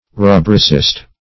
Search Result for " rubricist" : The Collaborative International Dictionary of English v.0.48: Rubrician \Ru*bri"cian\, Rubricist \Ru"bri*cist\, n. One skilled in, or tenaciously adhering to, the rubric or rubrics.